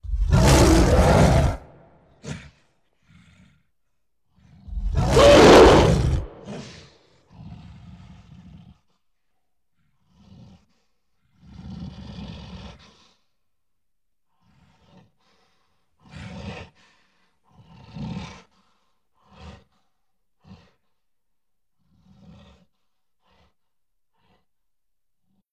gorilla-roar-sound